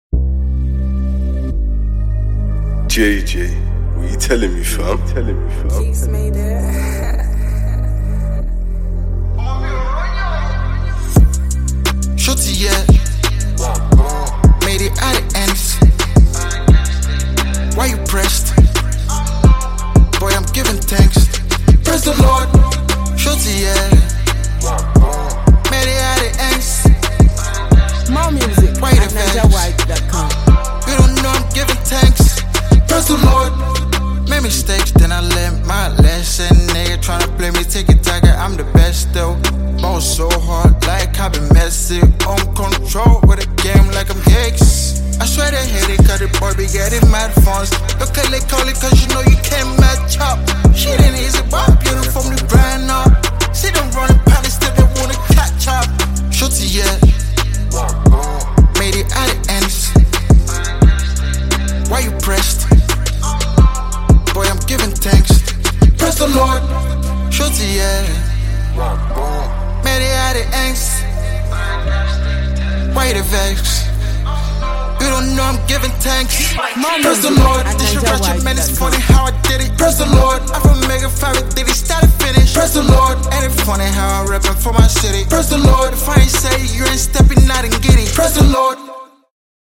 Multi-skilled Nigerian rapper, singer and Pop Entertainer